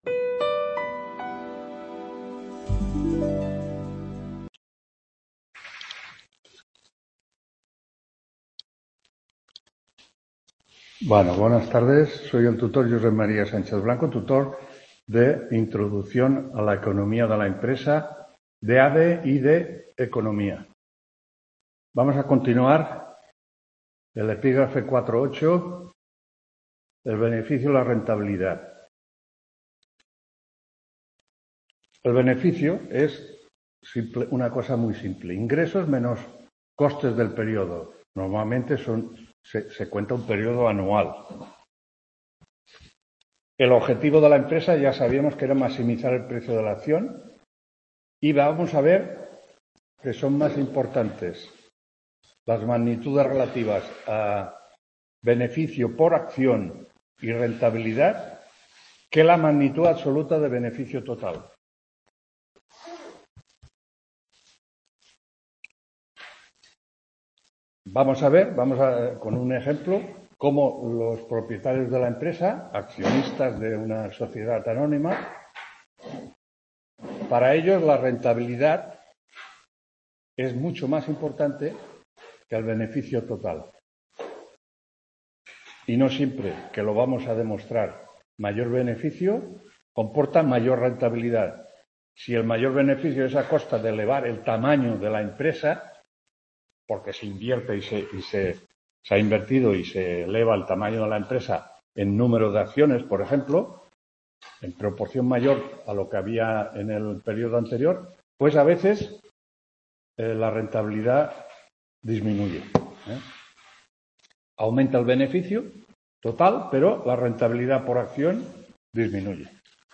9ª TUTORÍA INTRODUCCIÓN A LA ECONOMÍA DE LA EMPRESA 10… | Repositorio Digital